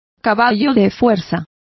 Complete with pronunciation of the translation of horsepower.